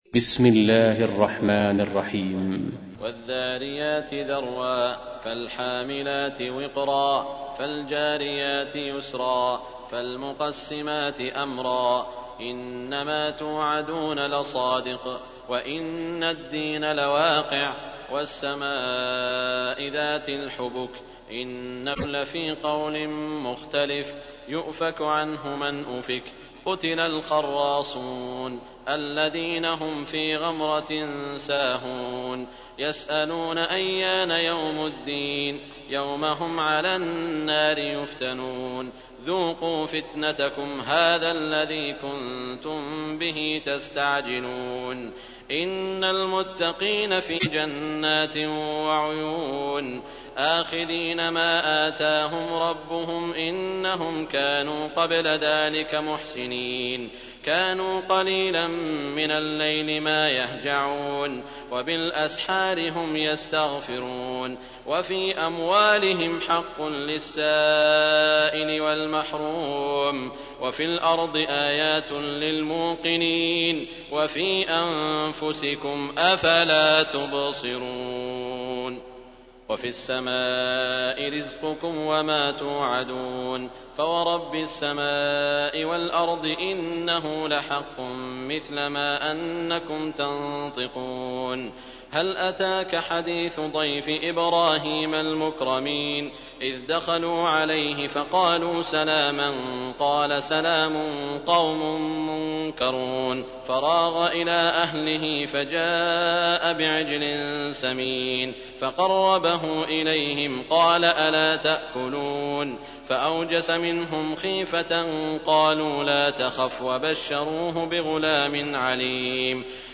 تحميل : 51. سورة الذاريات / القارئ سعود الشريم / القرآن الكريم / موقع يا حسين